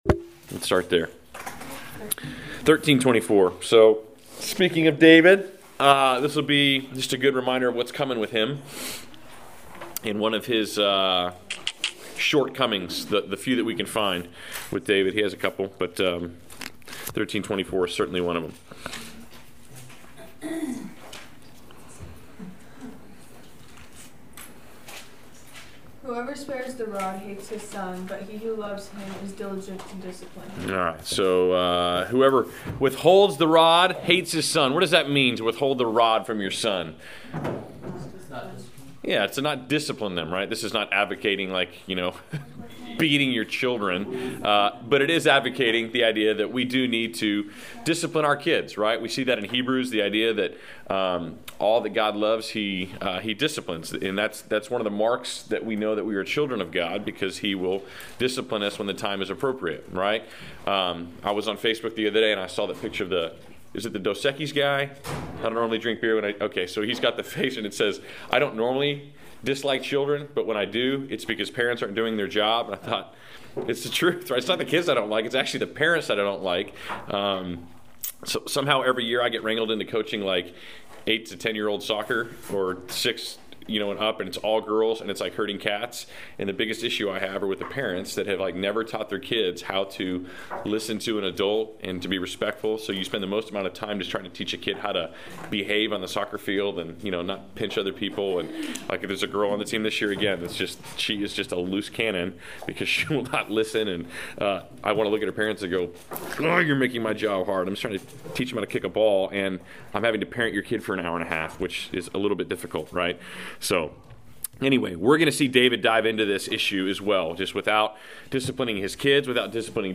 Class Session Audio April 04